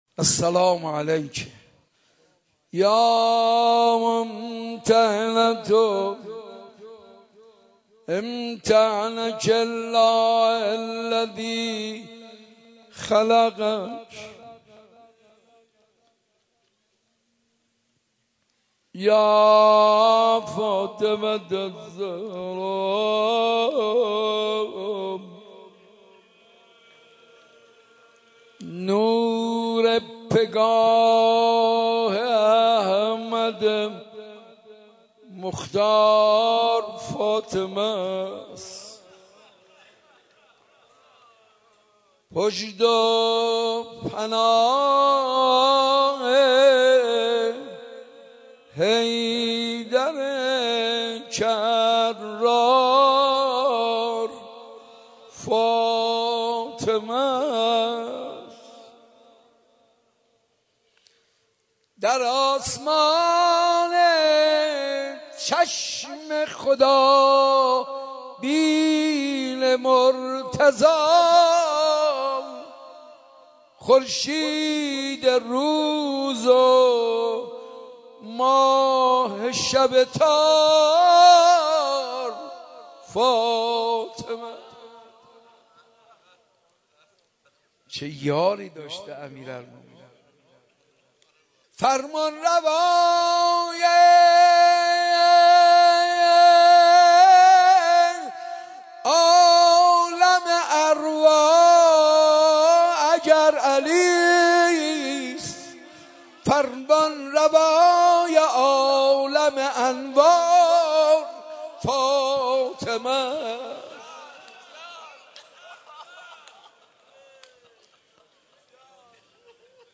روضه خوانی فاطمیه
[روضه]